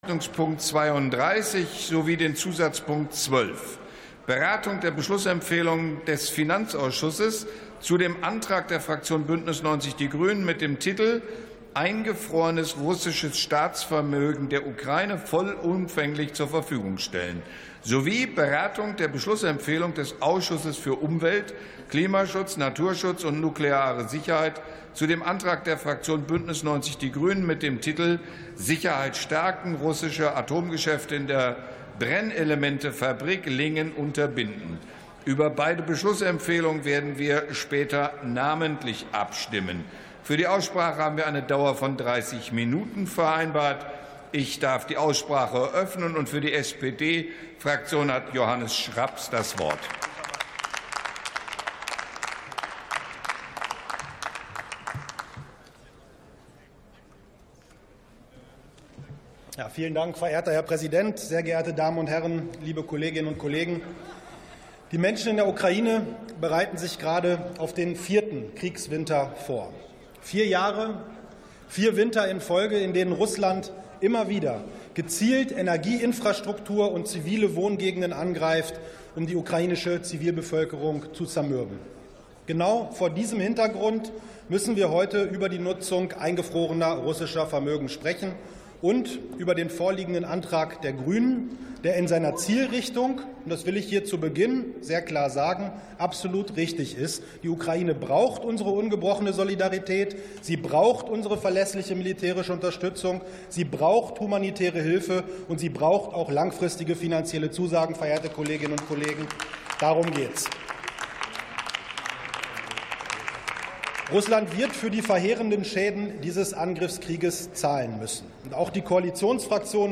Sitzung vom 05.12.2025. TOP 32, ZP 12: Eingefrorenes russisches Staatsvermögen für die Ukraine ~ Plenarsitzungen - Audio Podcasts Podcast